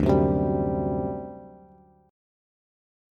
D11 Chord
Listen to D11 strummed